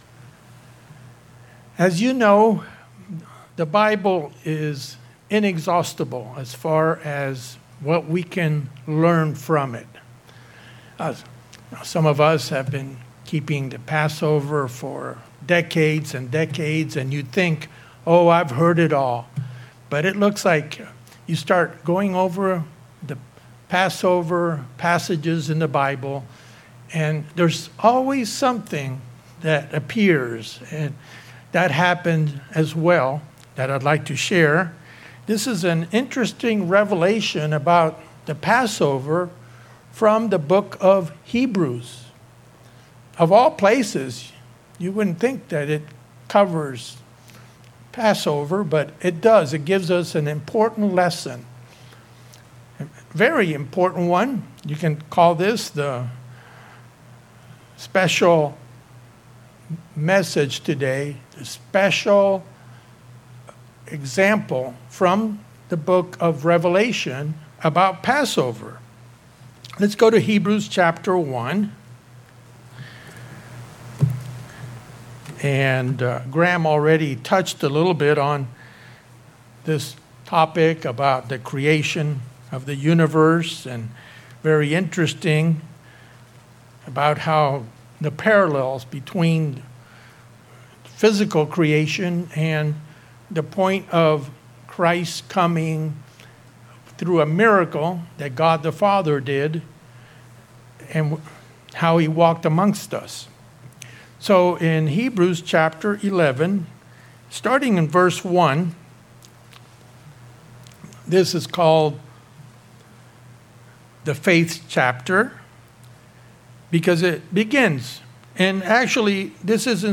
This message examines faith in the lives of Moses and Esther. There are three parts that we should consider as we may undergo suffering for the cause of Jesus Christ.